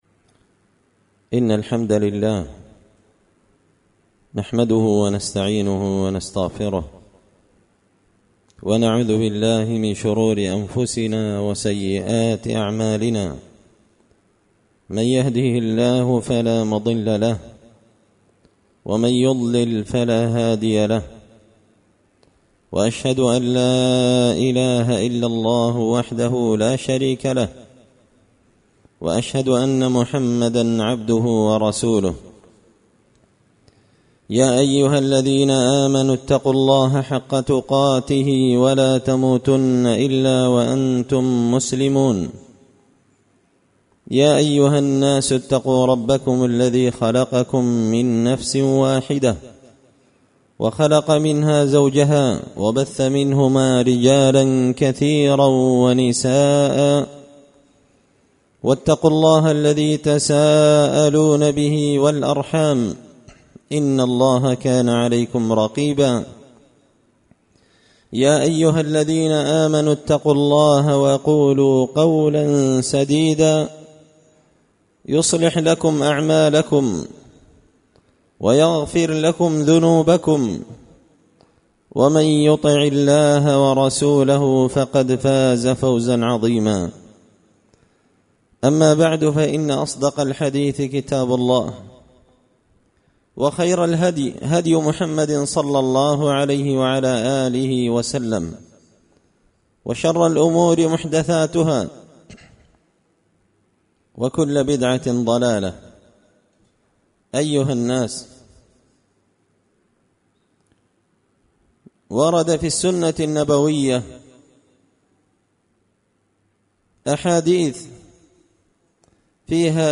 خطبة جمعة بعنوان – ضمانات نبوية
دار الحديث بمسجد الفرقان ـ قشن ـ المهرة ـ اليمن